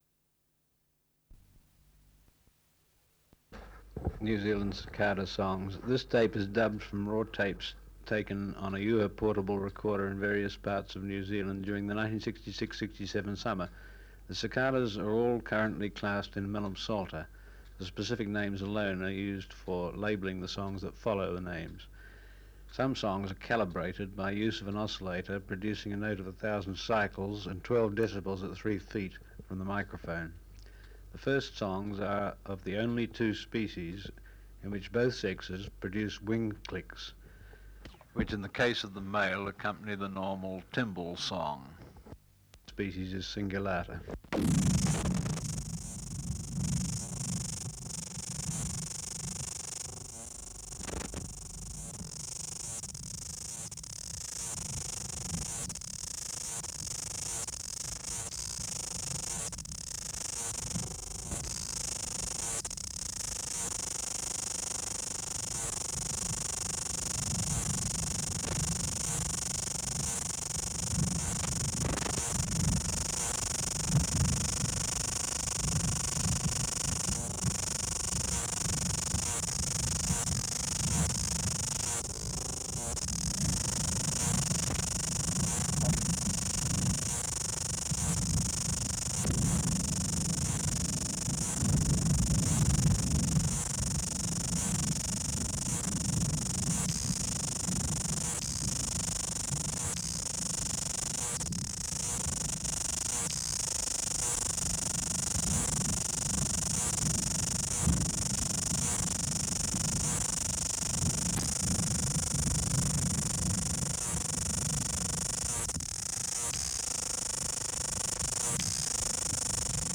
Species: Amphipsalta zelandica